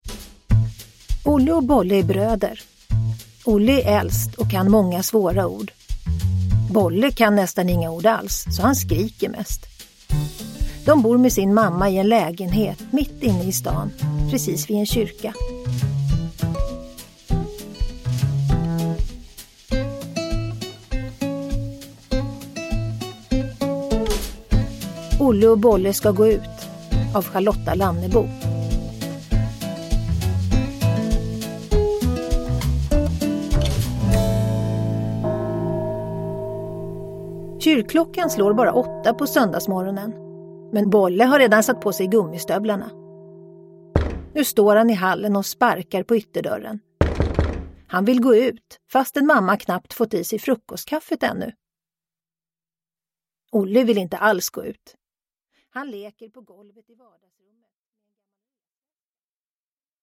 Olle och Bolle ska gå ut – Ljudbok – Laddas ner
Uppläsare: Tova Magnusson